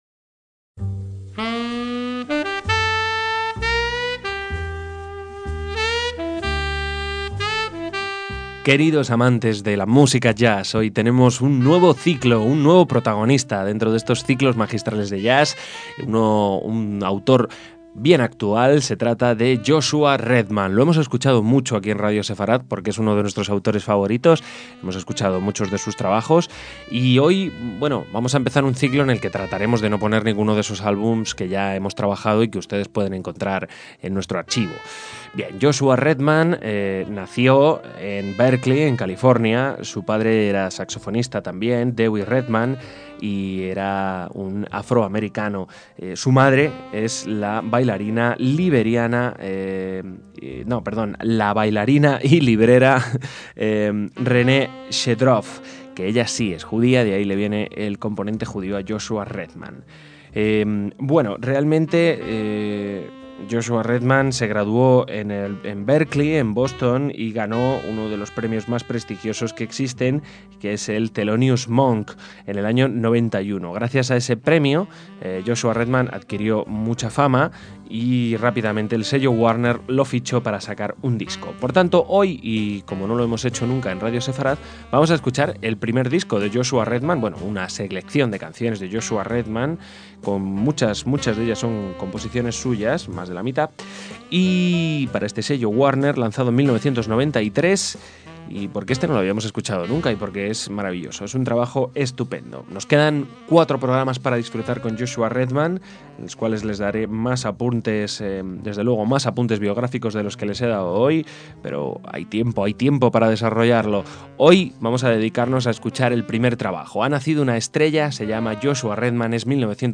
saxofonista de jazz